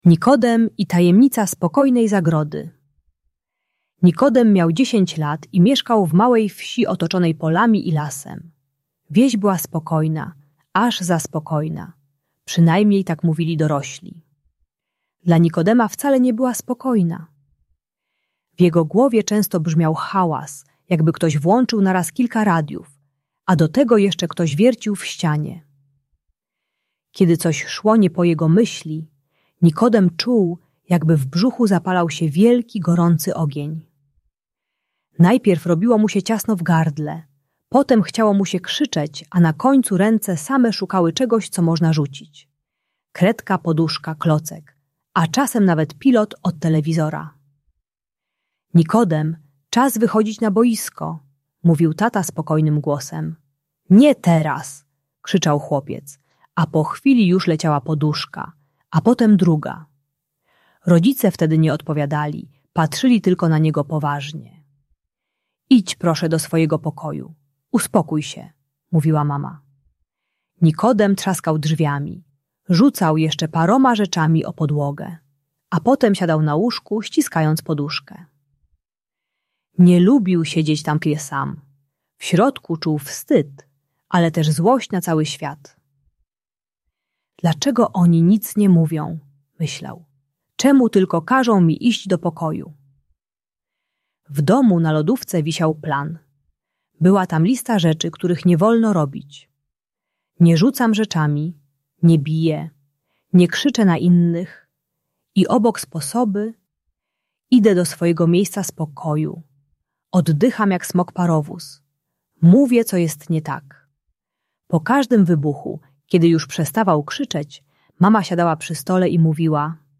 Nikodem i Tajemnica Spokojnej Zagrody - Bunt i wybuchy złości | Audiobajka